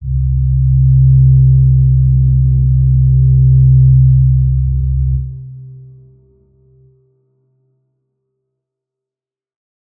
G_Crystal-C3-pp.wav